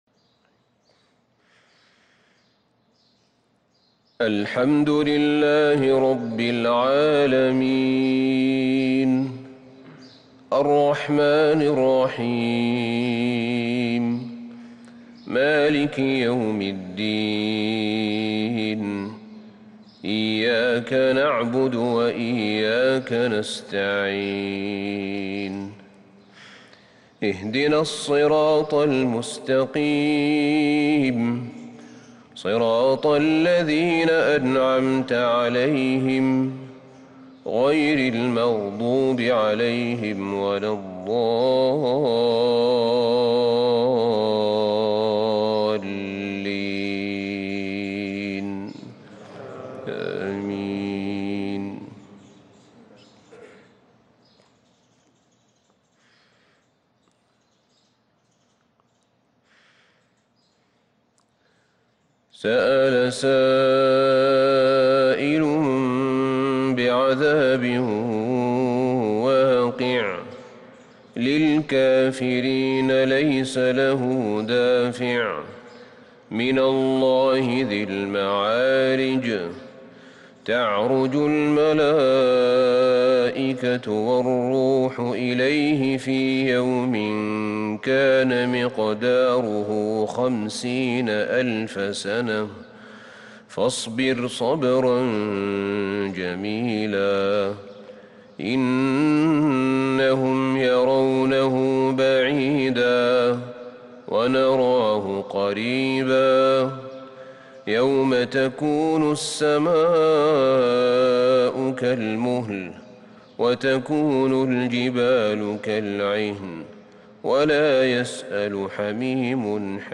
فجر الخميس 2-7-1443هـ سورتي المعارج و الفجر | Fajr prayer from Surah AlMa'arij And al-Fajr 3-2-2022 > 1443 🕌 > الفروض - تلاوات الحرمين